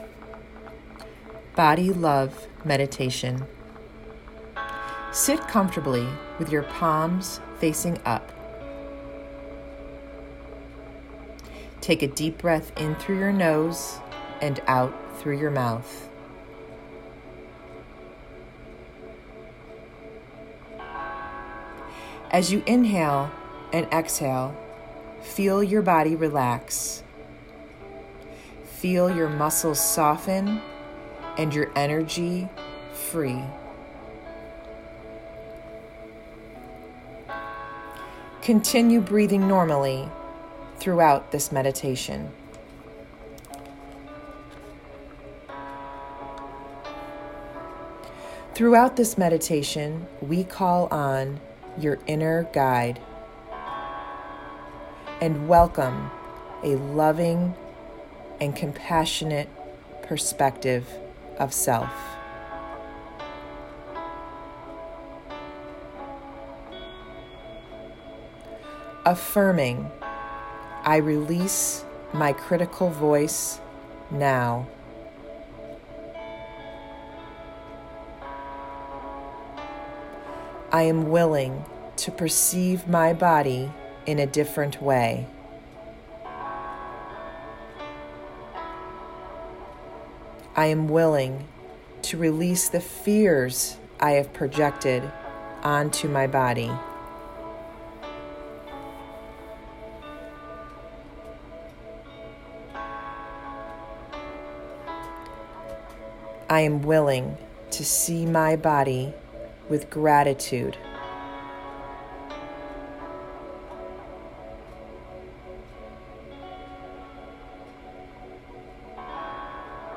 The meditation I am sharing with you is a Body Love Meditation from Gabby Bernstein.